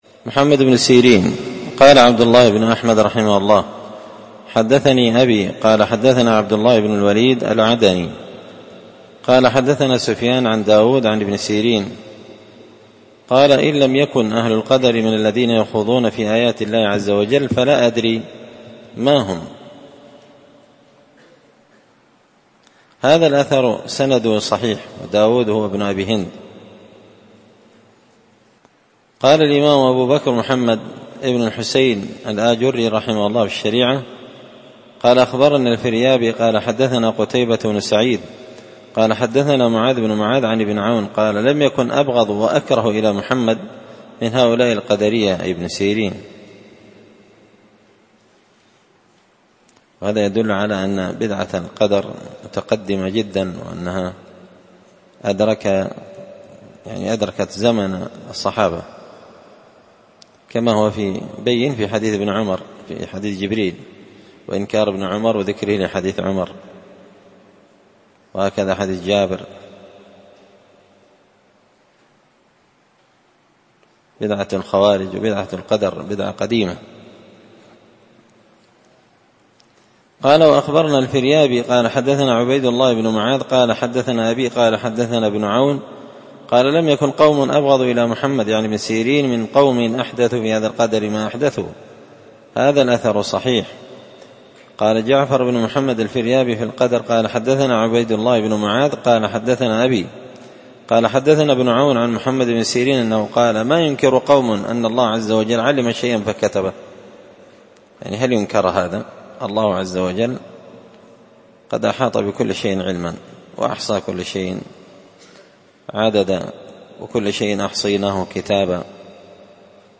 الدرس 132 فصل في ماصح عن السلف في القدر
دار الحديث بمسجد الفرقان ـ قشن ـ المهرة ـ اليمنالخميس 23 ربيع الثاني 1444هـــ